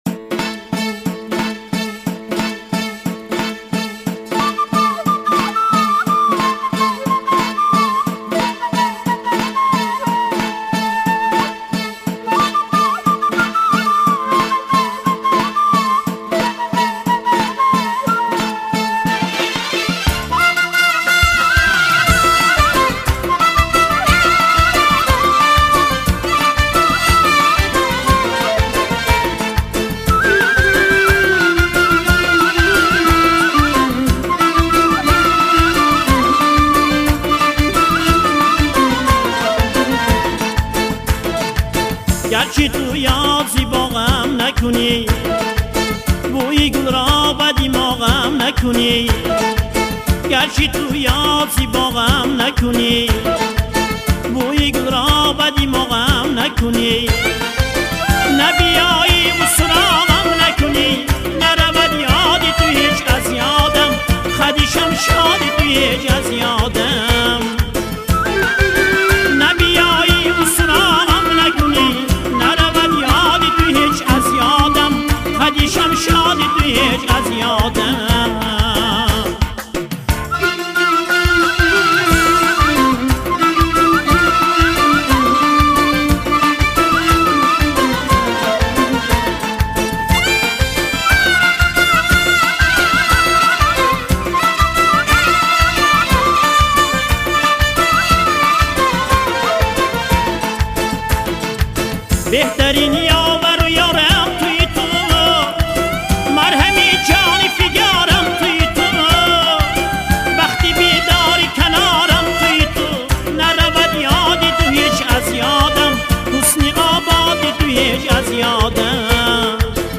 Главная » Файлы » Каталог Таджикских МР3 » Халки-Народный